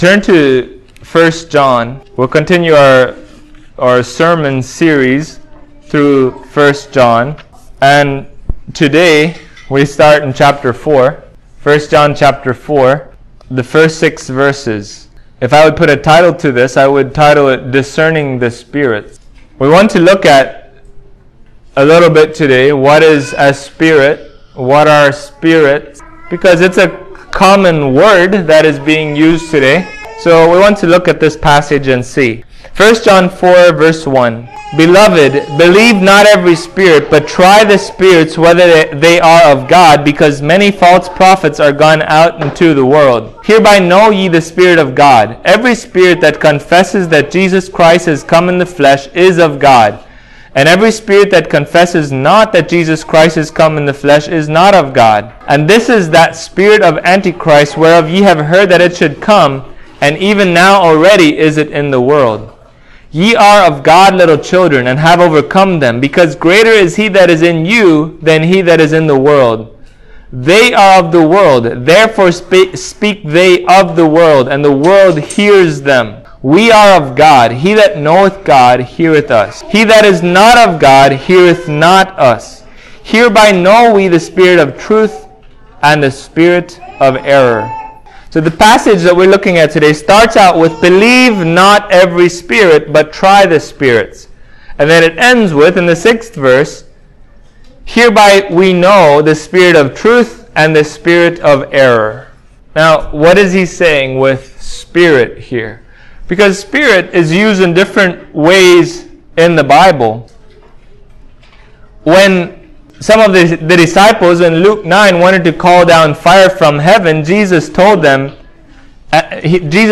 1John 4:1-6 Service Type: Sunday Morning Christians cannot be held captive by demonic spirits.